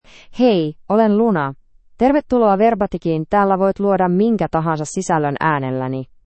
Luna — Female Finnish AI voice
Luna is a female AI voice for Finnish (Finland).
Voice: LunaGender: FemaleLanguage: Finnish (Finland)ID: luna-fi-fi
Voice sample
Listen to Luna's female Finnish voice.
Luna delivers clear pronunciation with authentic Finland Finnish intonation, making your content sound professionally produced.